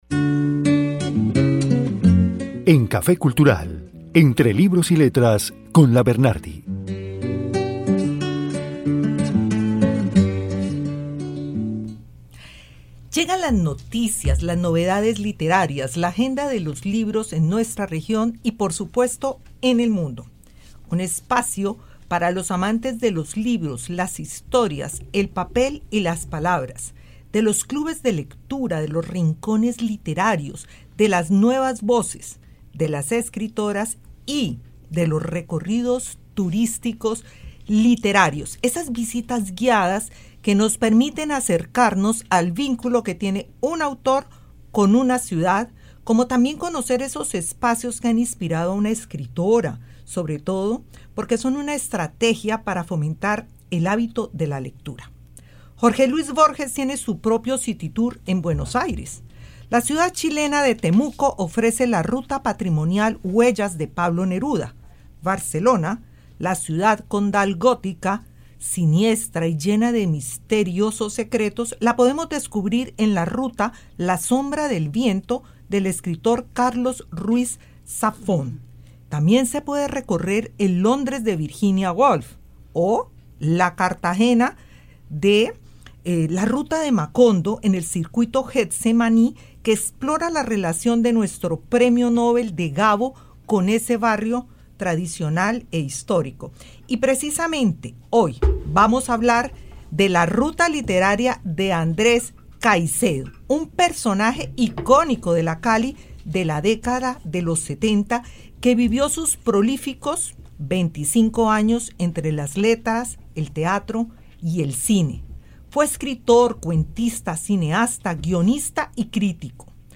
Esta entrevista fue en la emisora Carvajal la clásica 88.5 , en el programa café Cultural